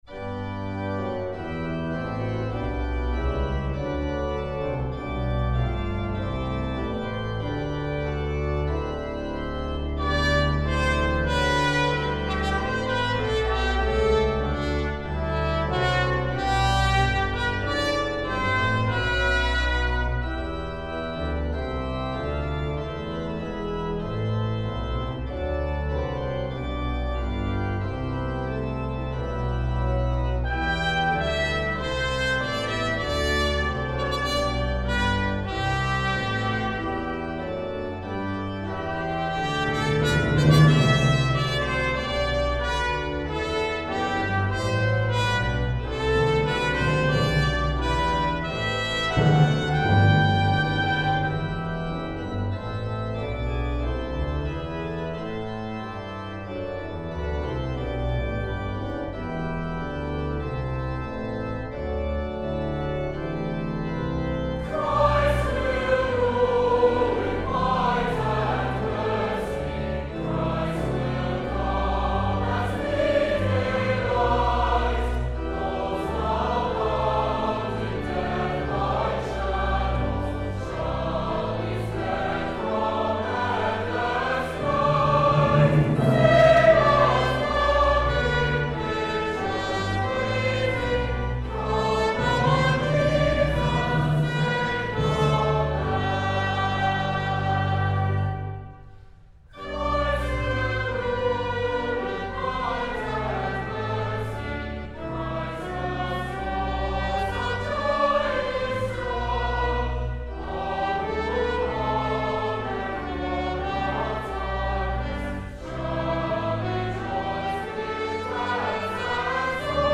SATB, Organ, Trumpet, Assembly